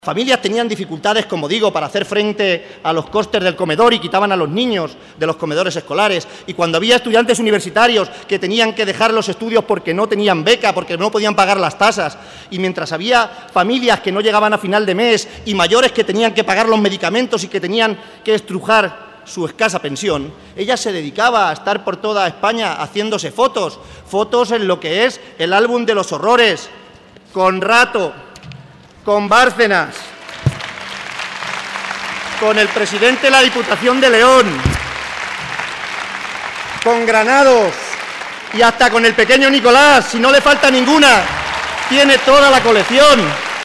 El candidato del PSOE a la Presidencia de Castilla-La Mancha, Emiliano García-Page, ha compartido esta tarde un gran acto público junto al secretario general socialista, Pedro Sánchez, en Alcázar de San Juan, ante más de 1.500 personas, y allí ha advertido una vez más que «el contador sigue en marcha, y va marcha atrás, como la región estos cuatro años, y seguimos sin conocer el programa electoral de Cospedal».